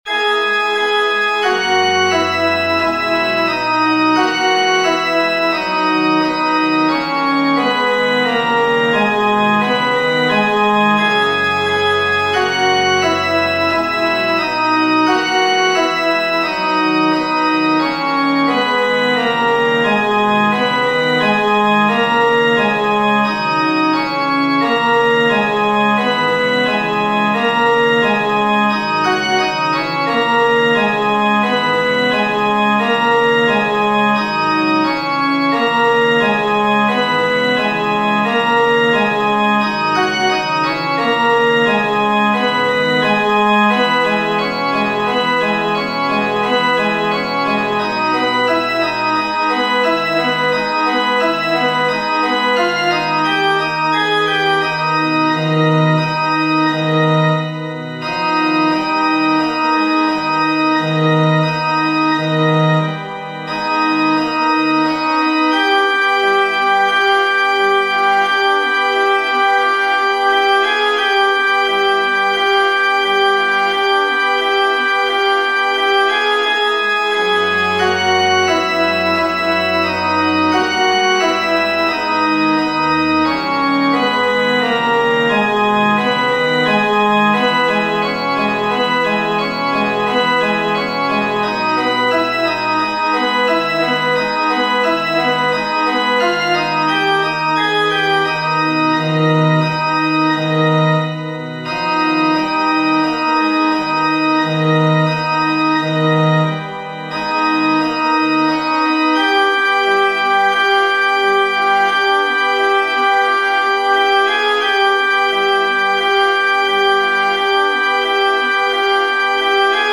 FF:HV_15b Collegium male choir
Rozlouceni-1T.mp3